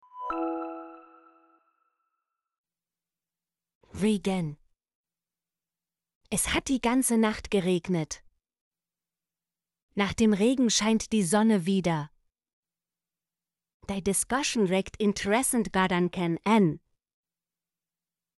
regen - Example Sentences & Pronunciation, German Frequency List